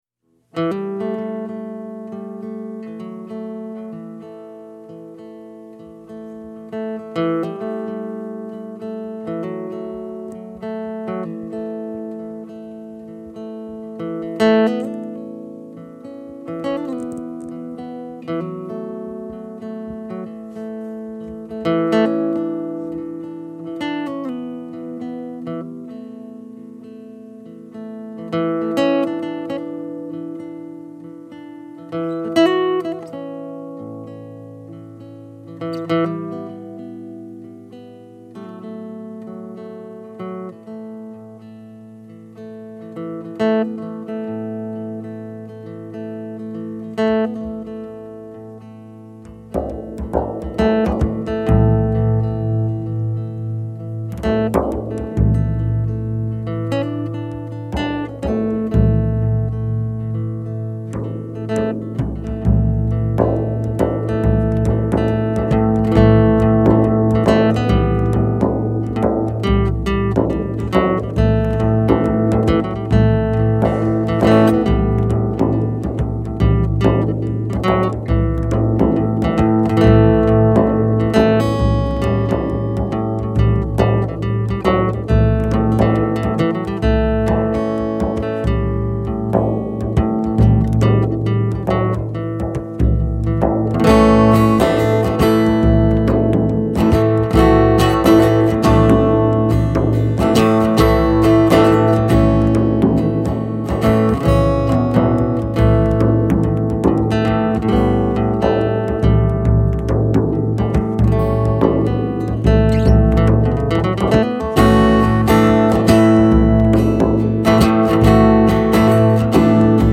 Poultney VT